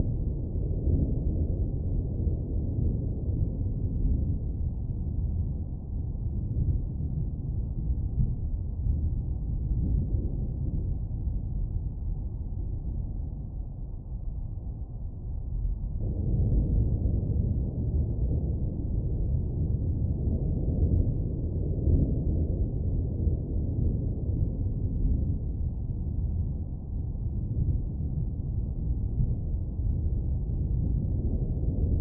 More ambience :)
thunder.ogg